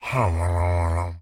Minecraft Version Minecraft Version snapshot Latest Release | Latest Snapshot snapshot / assets / minecraft / sounds / mob / evocation_illager / idle4.ogg Compare With Compare With Latest Release | Latest Snapshot